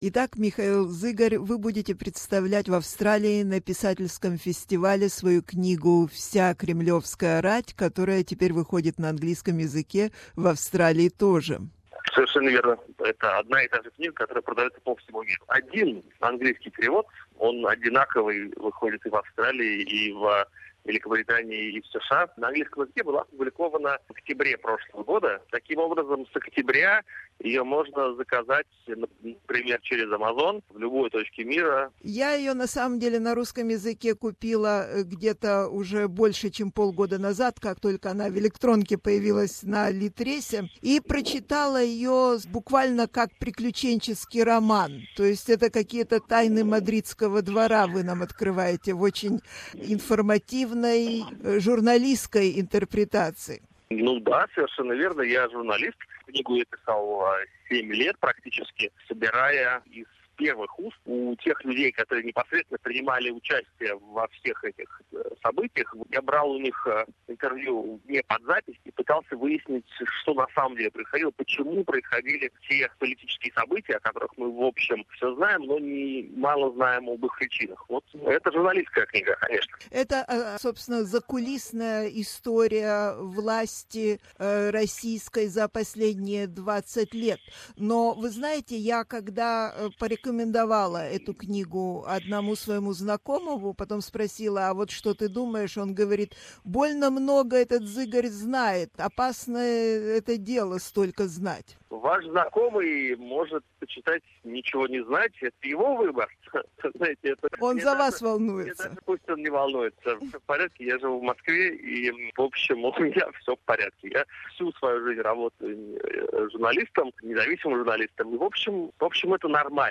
Его последняя книга, "Вся кремлевская рать" основана на беспрецедентной серии интервью с ближайшим окружением Владимира Путина, представляя радикально новый взгляд на власть и политику России. Незадолго до его приезда в Австралию на Сиднейский писательский фестиваль, мы побеседовали об этой книге и очень интересном историческом интернет-проекте Project1917.